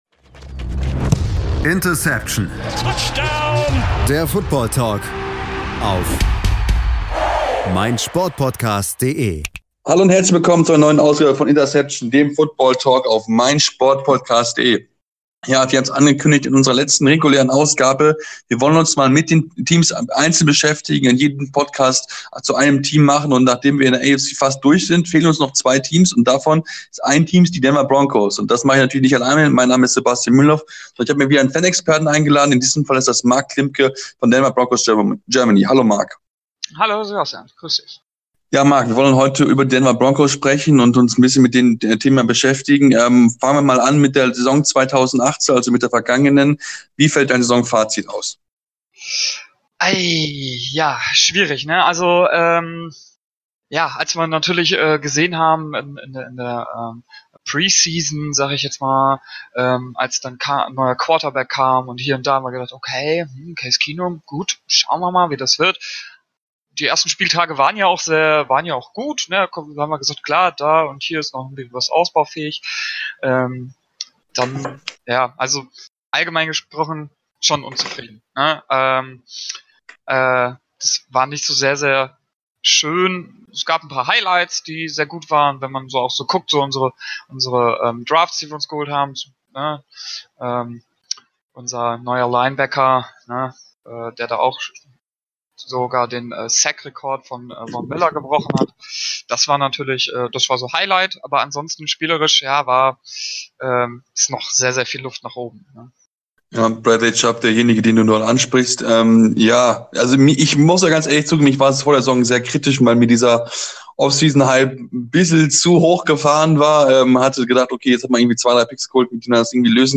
Es gibt eine Ausgabe zu jedem Team in der NFL, wo unsere Crew mit Fan-Experten über die jeweiligen Teams sprechen.